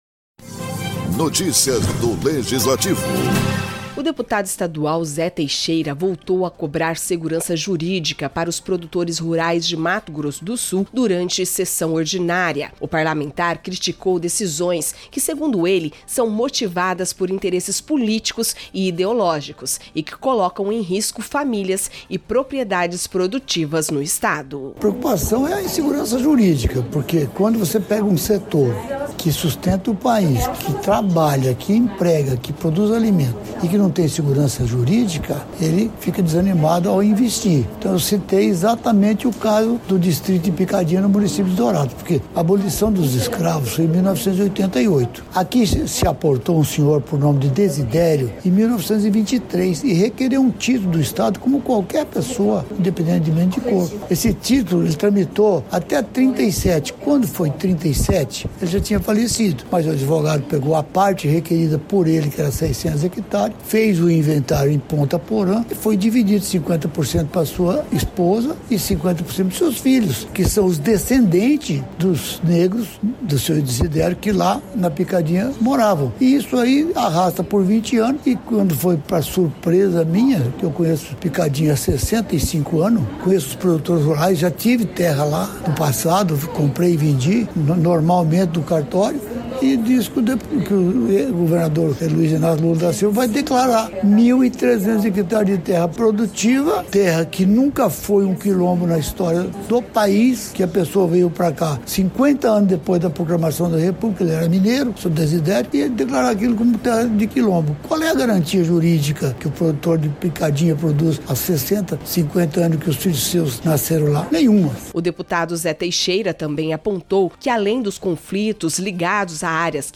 Durante sessão ordinária, o deputado Zé Teixeira voltou a criticar decisões que, segundo ele, ameaçam produtores rurais em Mato Grosso do Sul.